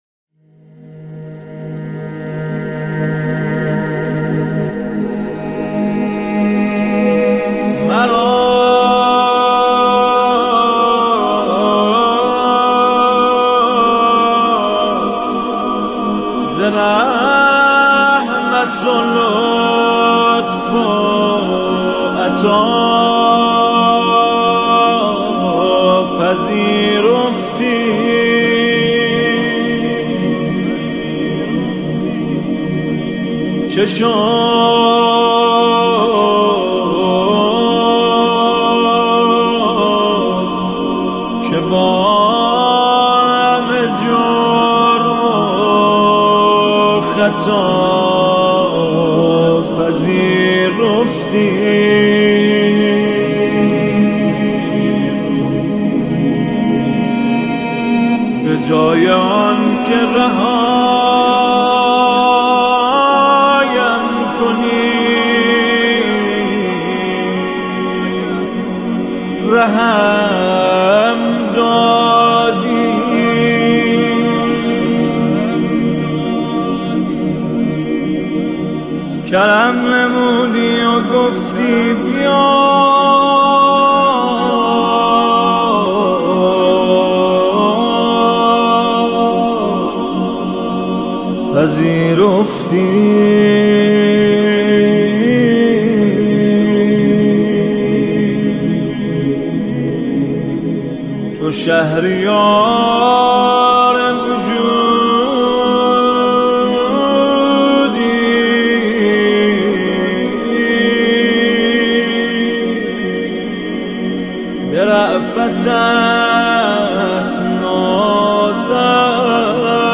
نماهنگ زیبا و دلنشین